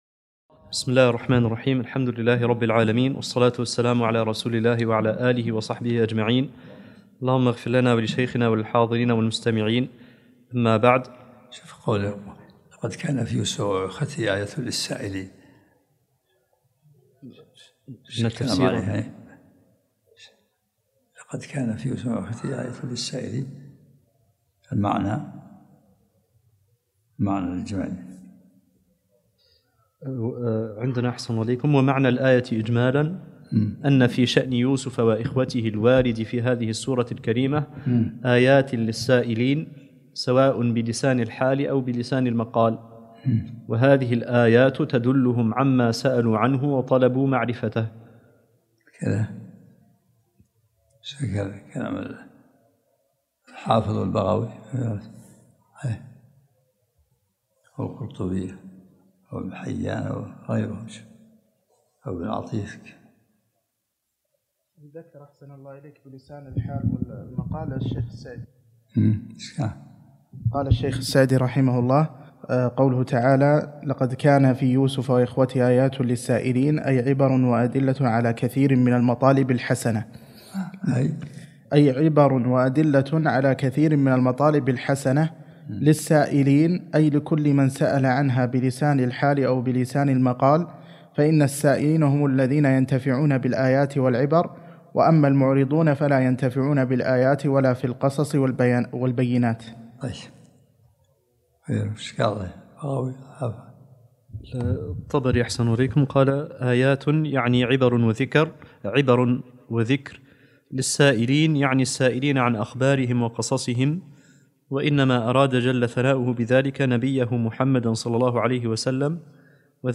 الدرس السابع عشرمن سورة يوسف